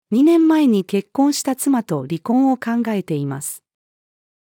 ２年前に結婚した妻と離婚を考えています。-female.mp3